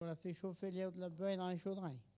Elle provient de Bouin.
Catégorie Locution ( parler, expression, langue,... )